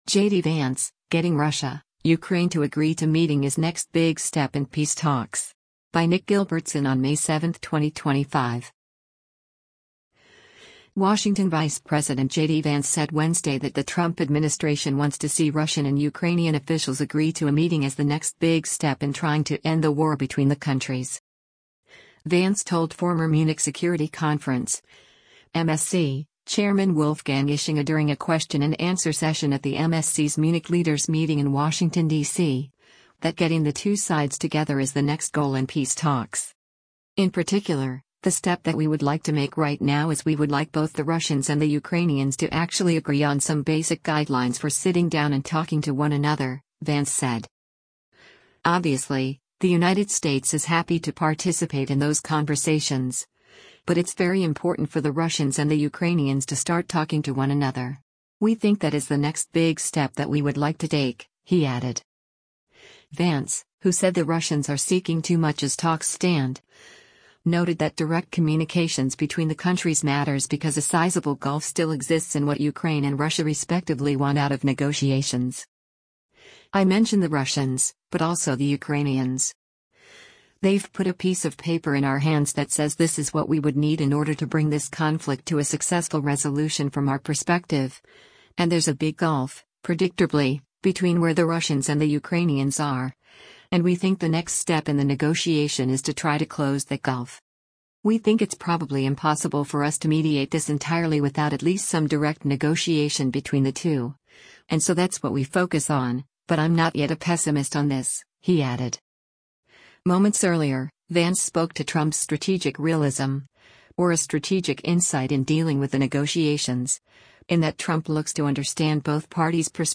Vance told former Munich Security Conference (MSC) chairman Wolfgang Ischinger during a question-and-answer session at the MSC’s Munich Leaders Meeting in Washington, DC, that getting the two sides together is the next goal in peace talks.